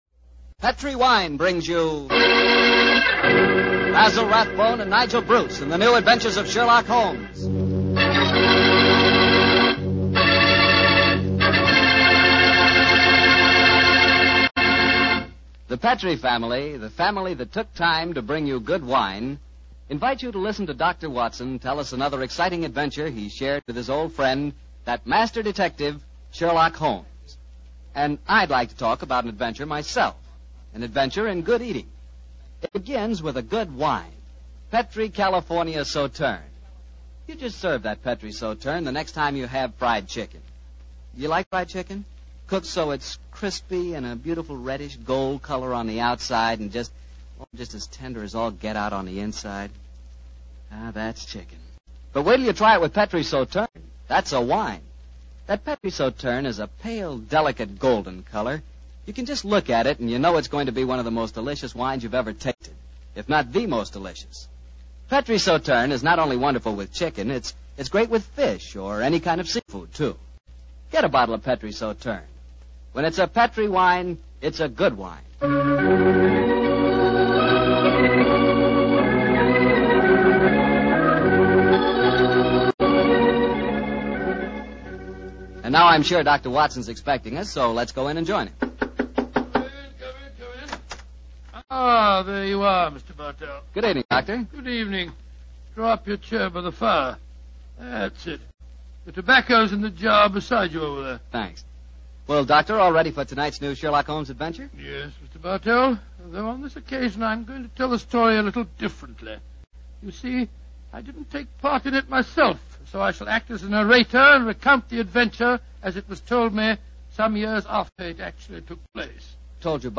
Radio Show Drama with Sherlock Holmes - Murder In The Himalayas 1946